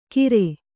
♪ kire